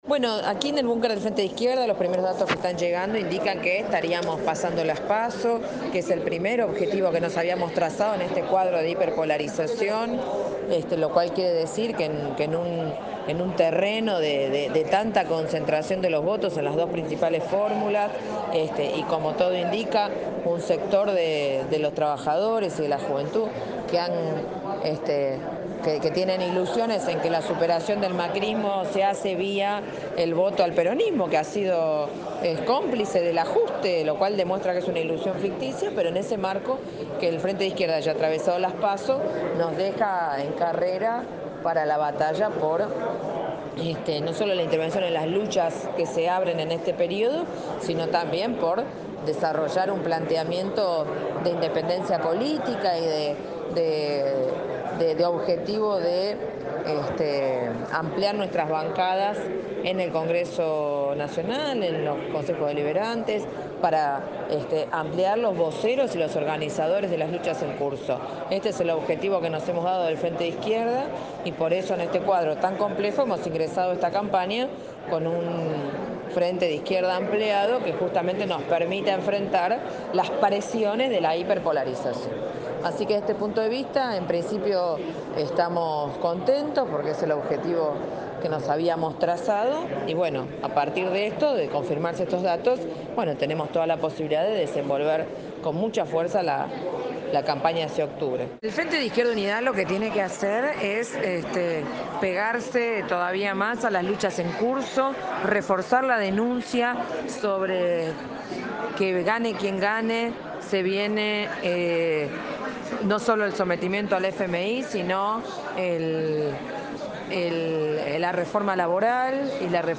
Romina Del Pla, candidata a vice por el FIT – Audio previo a la entrega de datos oficiales.-
“Lo que tiene que hacer el FIT es pegarse aun más a las luchas en curso, reforzar la denuncia sobre que gane quien gane se viene no solo el sometimiento al FMIs sino la reforma laboral y jubilatoria. Es muy importante alertando sobre las políticas que va a llevar adelante Alberto Fernández”, analizó Del Pla, en dialogo con este medio, desde su bunker, luego del cierre de los comicios.
ROMINA_DEL_PLA_BUNKER_FIT_ELECCIONES_PASO2019.mp3